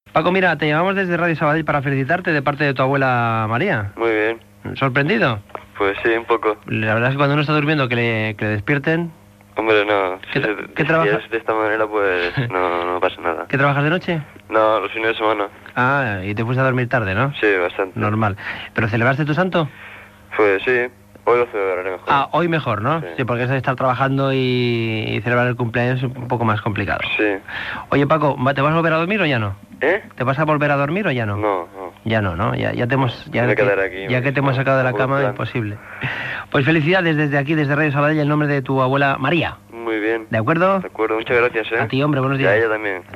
Secció de felicitacions telefòniques als oients
Entreteniment